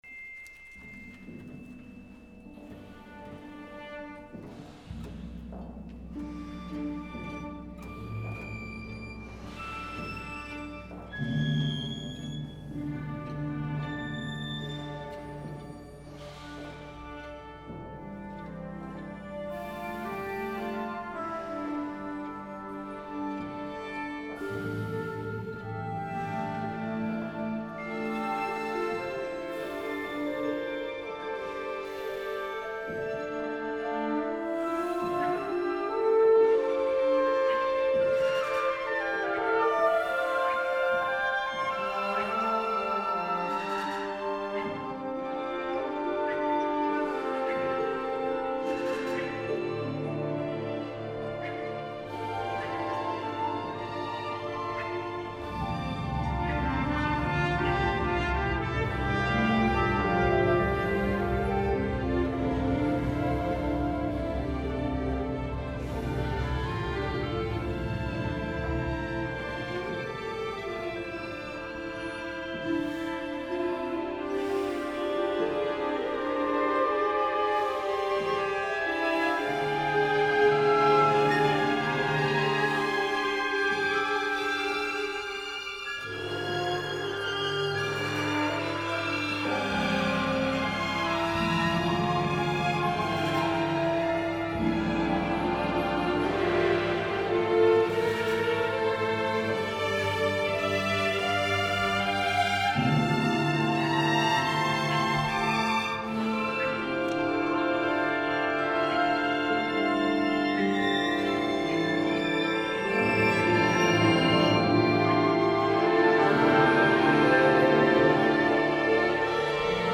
violoncelle solo